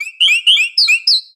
Cri de Plumeline Style Flamenco dans Pokémon Soleil et Lune.
Cri_0741_Flamenco_SL.ogg